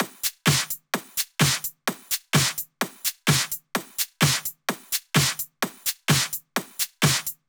VDE 128BPM Silver Drums 3.wav